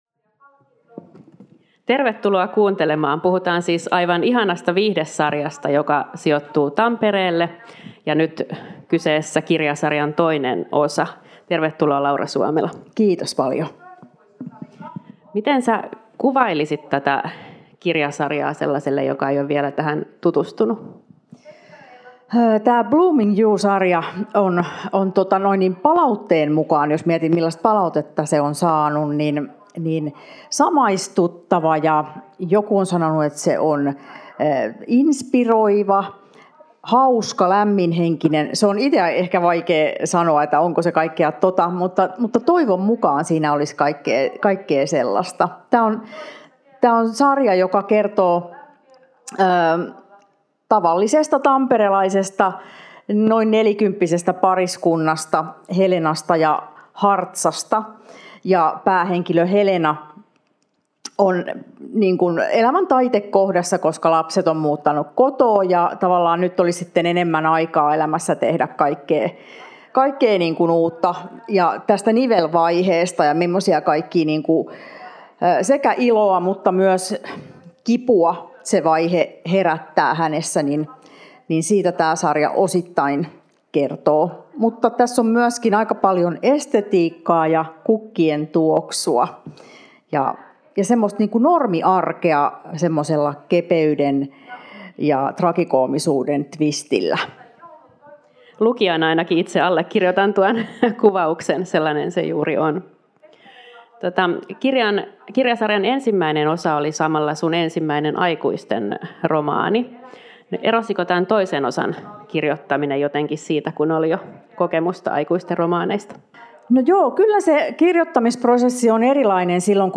Ohjelma on taltioitu Tampereen Kirjafestareilla 2024.